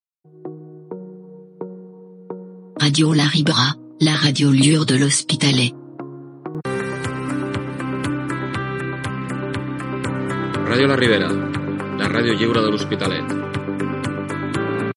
Indicatiu de l'emissora en francès i català